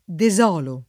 desolare v.; desolo [